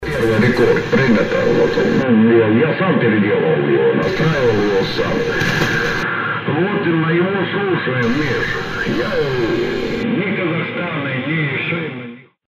В заключение предлагаю файлы с записями работы этого ФНЧ.
Фильтр работает 2_4,  6_8, 10_12 секунды.